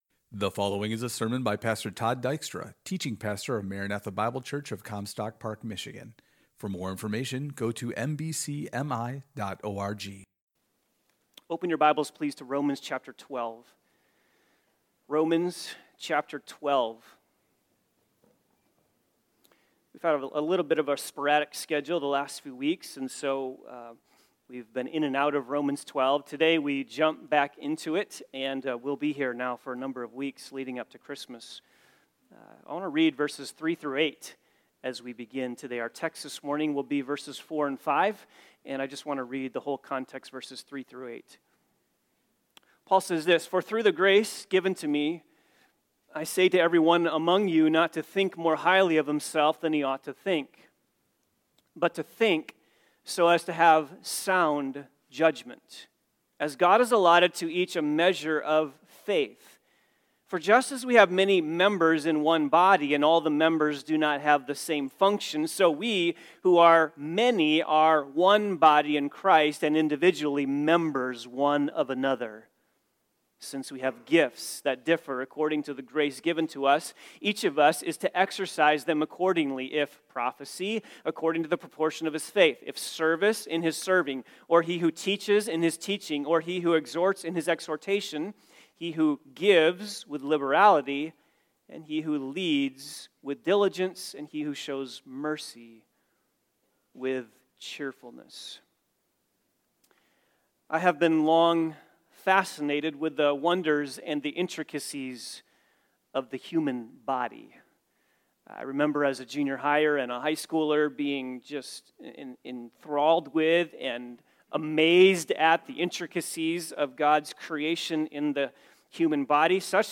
Four principles related to the giving of spiritual gifts The first sermon in this series: Part 1 – 1.